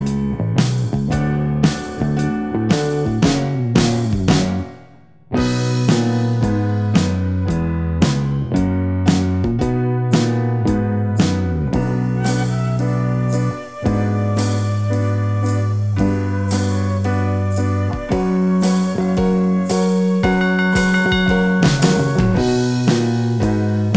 Minus Guitars Pop (1960s) 2:58 Buy £1.50